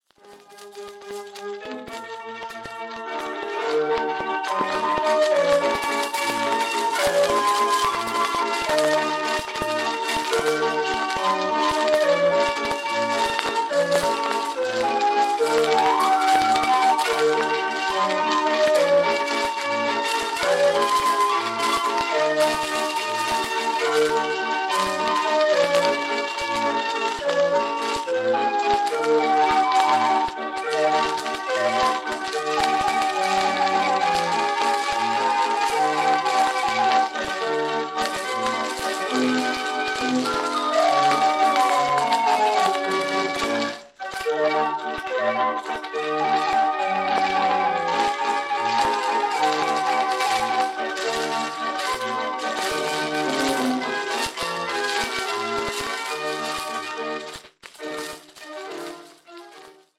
Formaat 78 toerenplaat, schellak